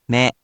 In romaji, 「め」 is transliterated as 「me」which sounds a bit like 「May」and looks a bit like a bowl of ramen with chop sticks sticking out of it.